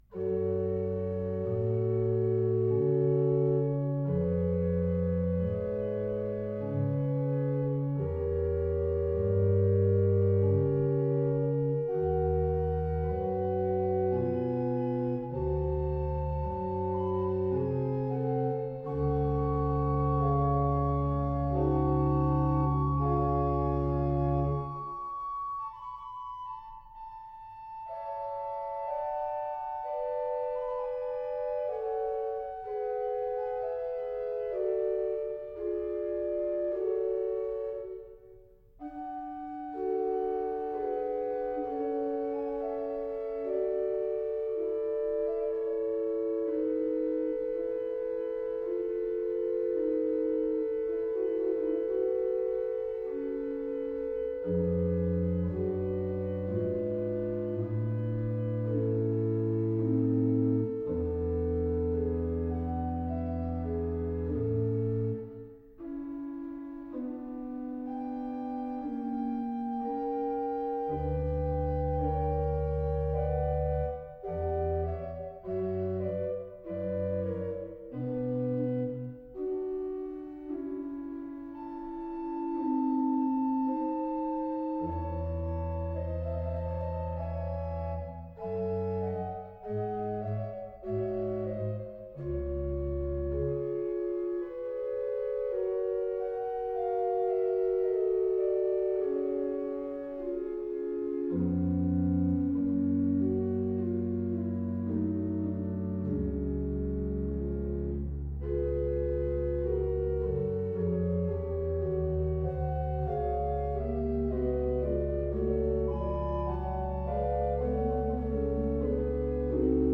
Adagio and Allegro in F minor, K.594 Organ
Style: Classical